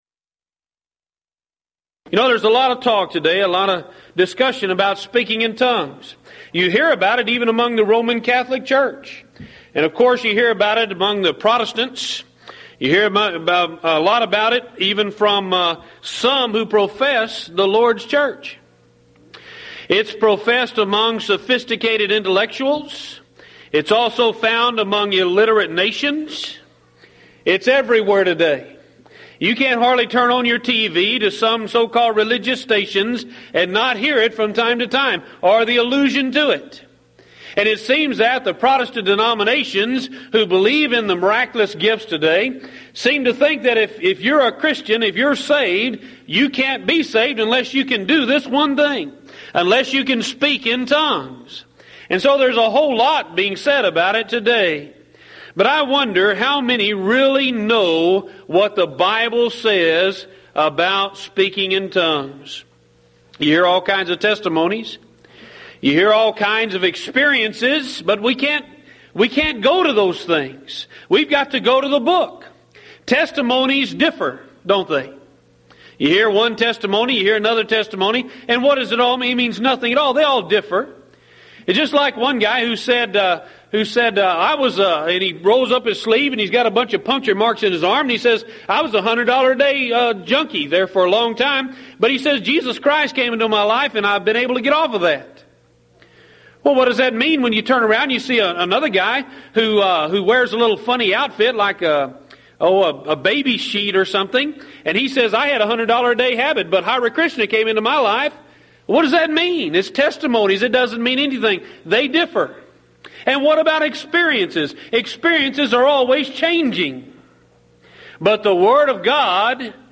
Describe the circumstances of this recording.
Event: 1997 Mid-West Lectures Theme/Title: God The Holy Spirit